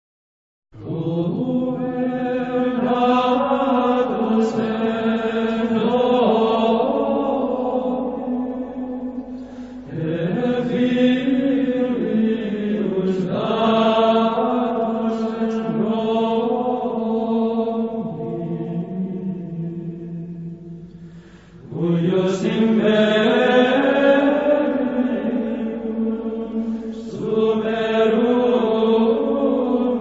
Introito (Modo 7.)